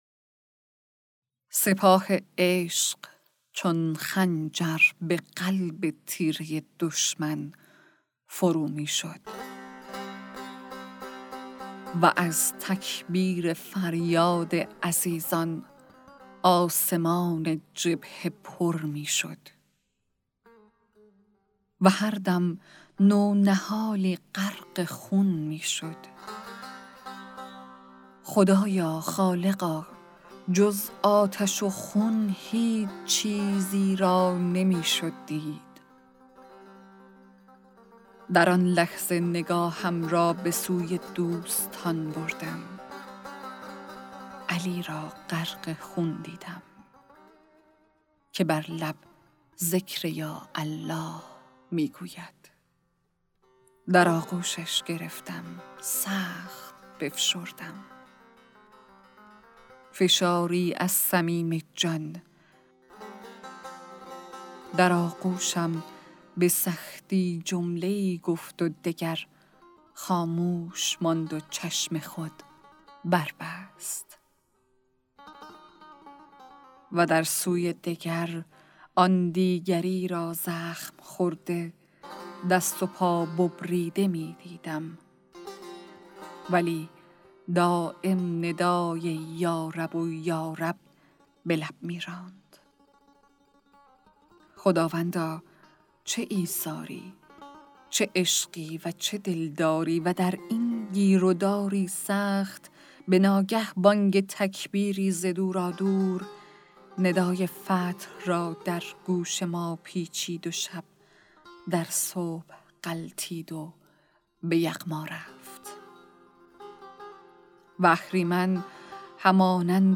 «شمال حماسه» کتاب صوتی اشعار شاعران شهید استان گیلان